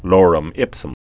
(lôrəm ĭpsəm)